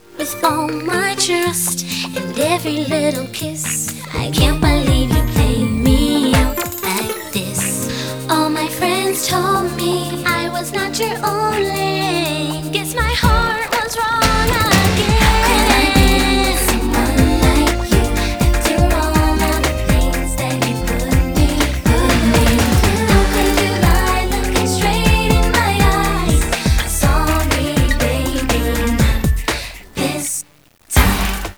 Here are wav-files from famous R&B artists